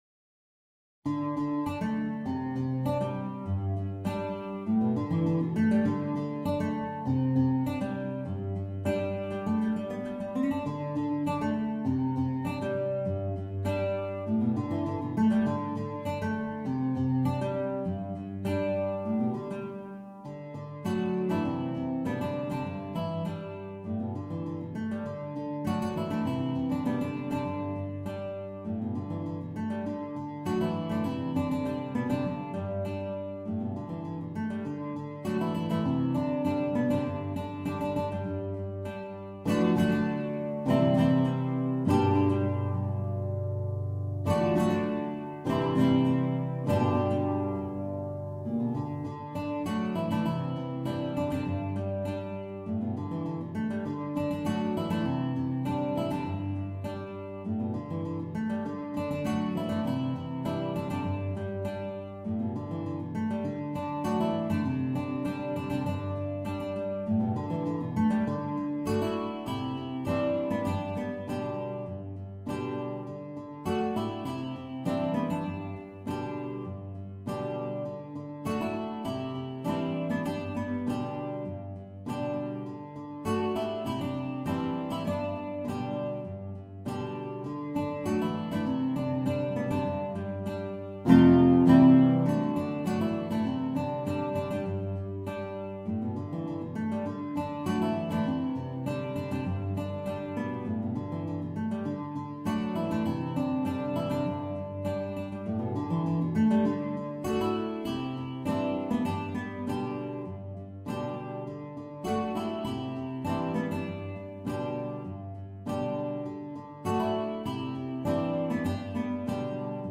MIDI Mockup Recording